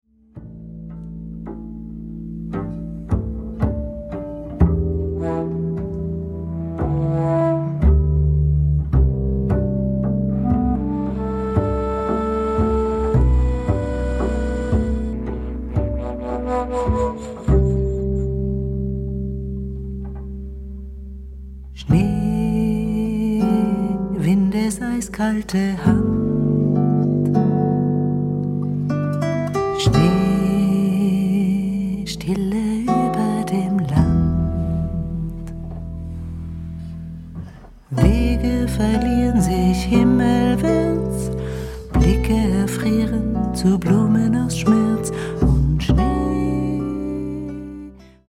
Gitarre, Bass, Ukulele, Gesang
Akkordeon
Klavier, Saxophone, Flöten